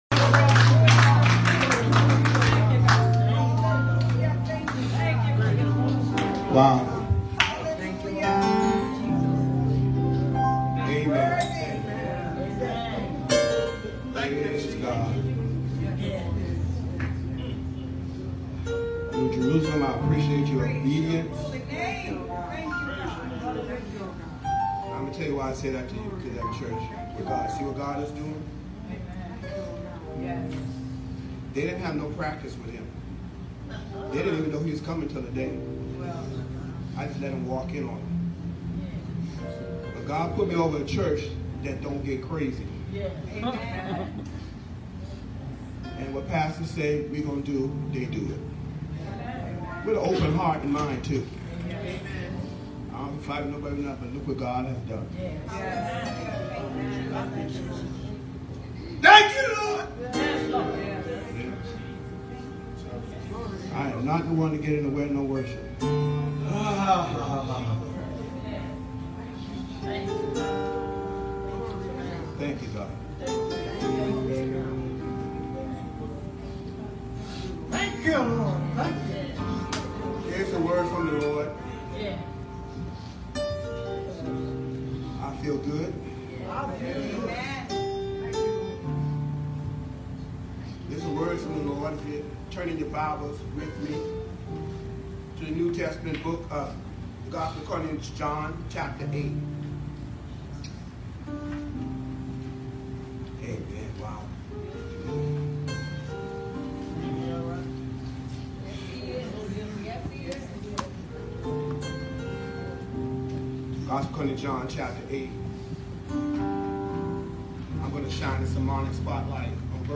September 29, 2019 Sunday 11;00am New Jerusalem MB Ch John 8:1-11 The Message: “DROP THAT ROCK”